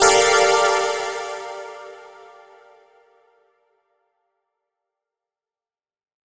Chords_G_01.wav